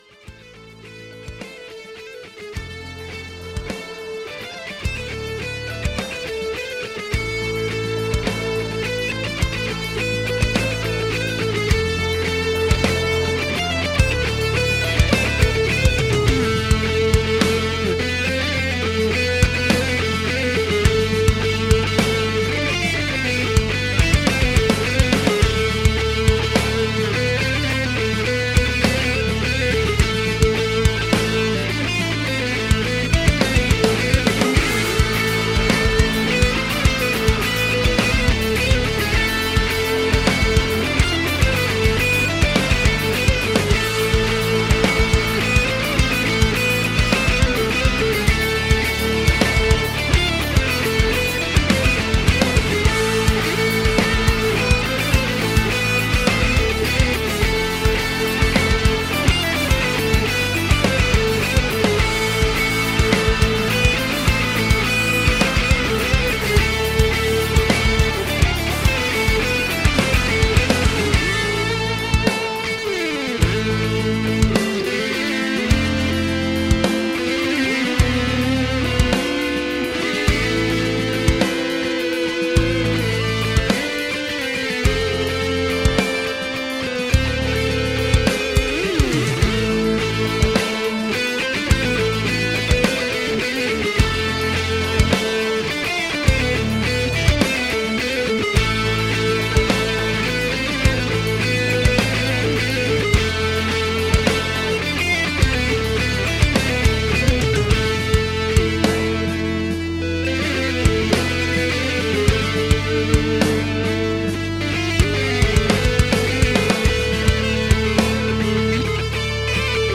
3 x guitars, 1 x bass, drums, and ambient effects